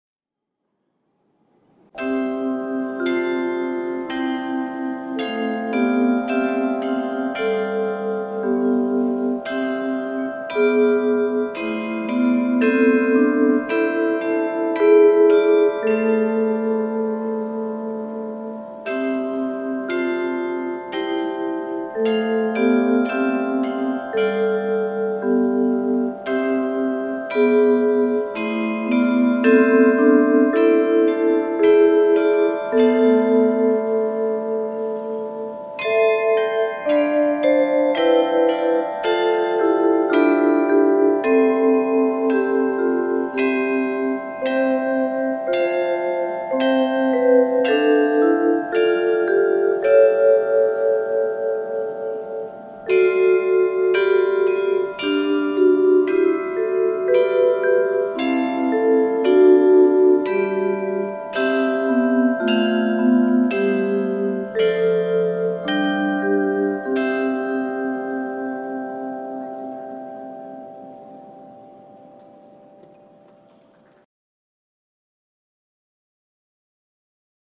実際の音色
実際の演奏で使った時の音源です。
被災地支援企画『遠い日にしない、あの時』で演奏しました。
そのためか演奏クオリティは低いです・・・
未熟な演奏ですが、カリヨンとビブラフォンの演奏は下記より視聴できます。